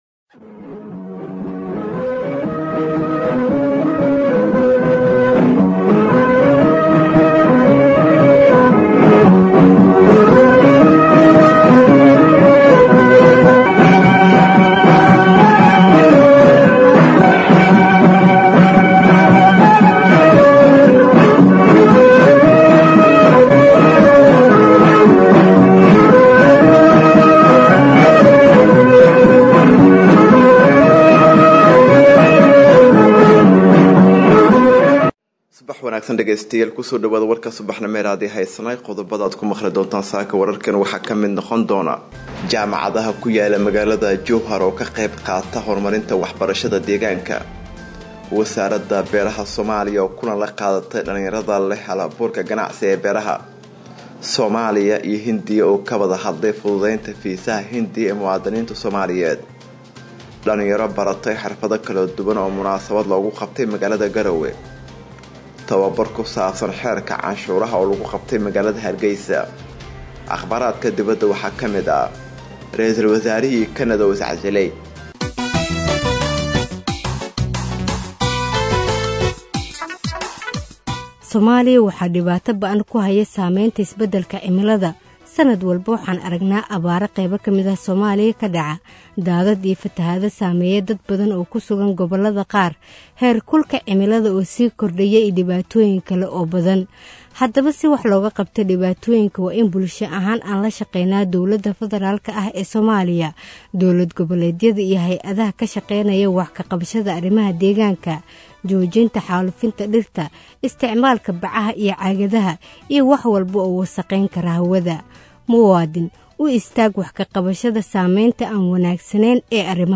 Warka Subaxnimo Ee Isnaay Radio